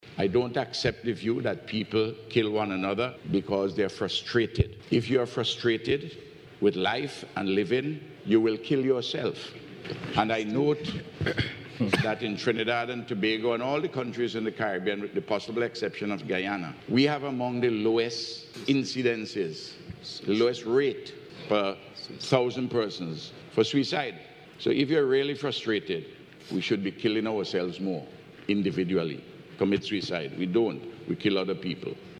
Dr. Gonsalves made the point while speaking at a two-day symposium in Trinidad and Tobago held with the theme Violence as a Public Health Issue – The Crime Challenge .